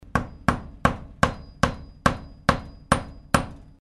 Звуки молотка судьи
На этой странице собраны звуки молотка судьи — от четких одиночных ударов до протяжных стуков.
Звук забивания гвоздей